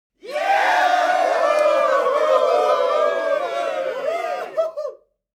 cheer-BsLm2-w7.wav